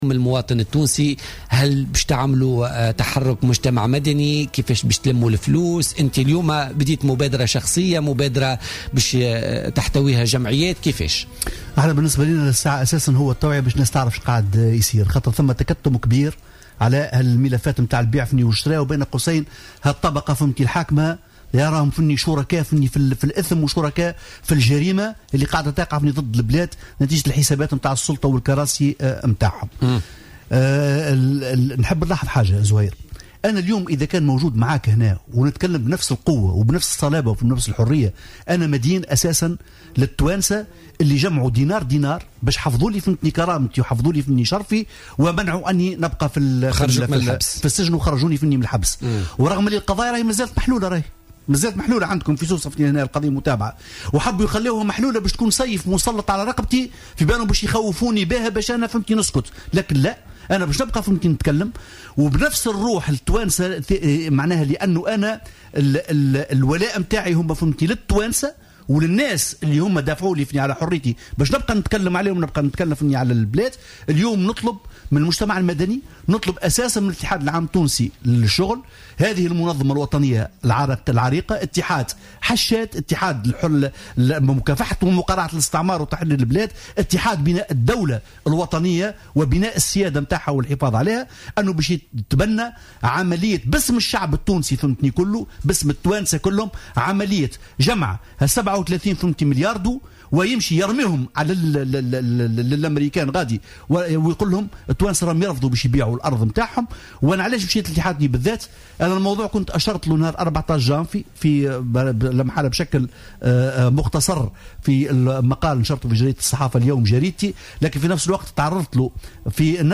ضيف برنامج بوليتكا